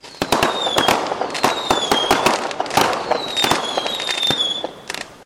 sounds_fireworks_02.ogg